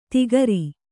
♪ tigari